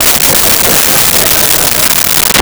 Crowd Laughing 07
Crowd Laughing 07.wav